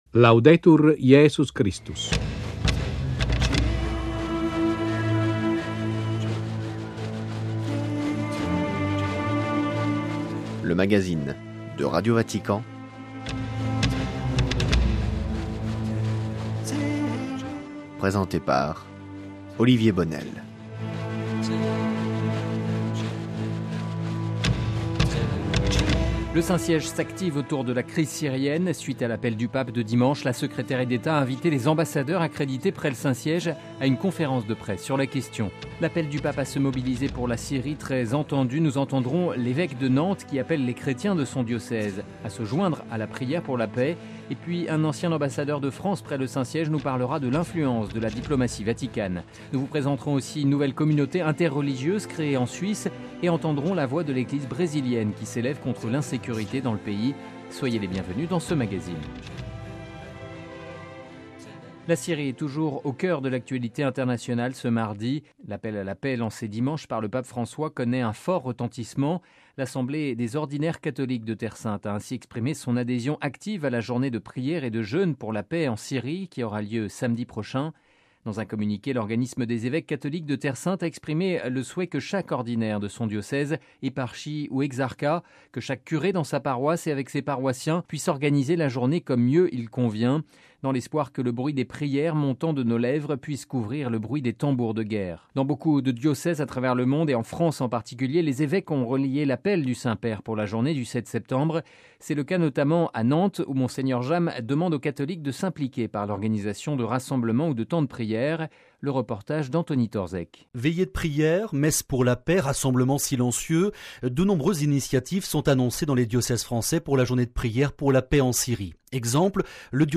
Sommaire: - L'appel du pape à la prière et le jeûne pour la Syrie, repris dans de nombreux diocèses. Reportage à Nantes dans l'ouets de la France